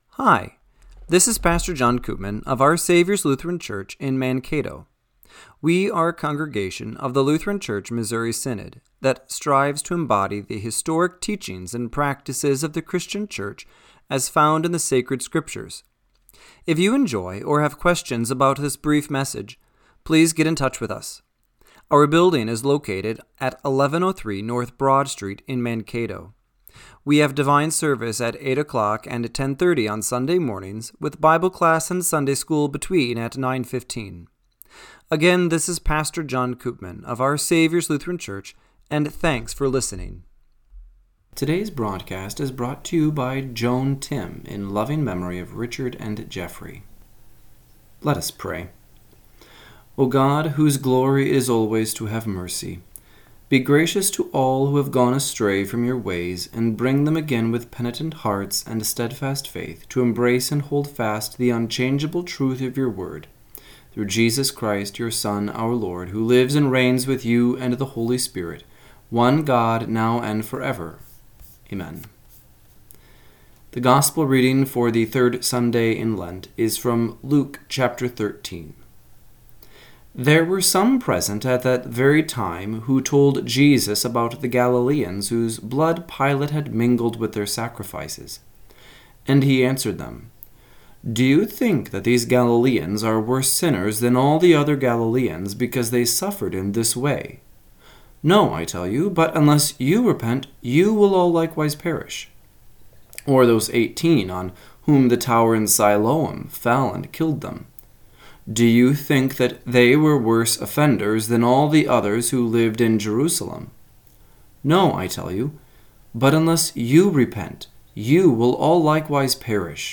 Radio-Matins-3-23-25.mp3